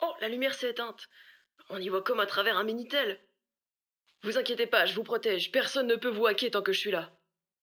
VO_LVL3_EVENT_Lumiere OFF_01.ogg